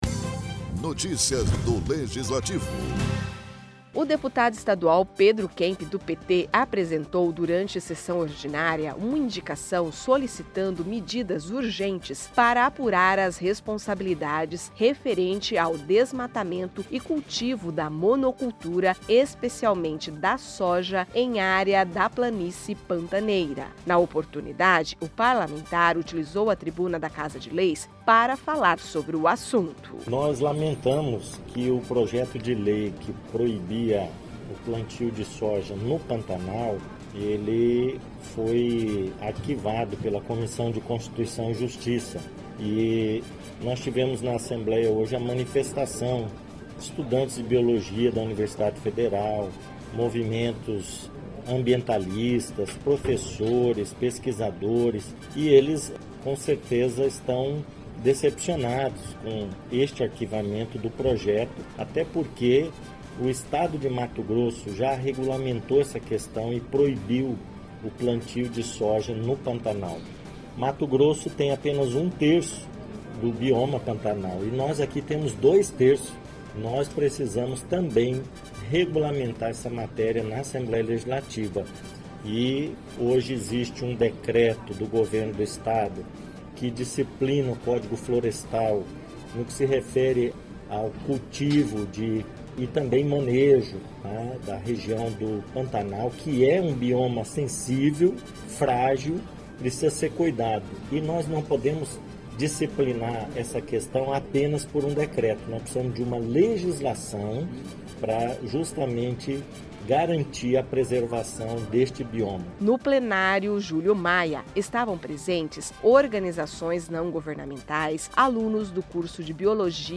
O deputado estadual Pedro Kemp (PT), apresentou durante sessão ordinária uma indicação solicitando medidas urgentes para apurar as responsabilidades referente ao desmatamento e cultivo da monocultura, especialmente da soja, em área da planície pantaneira.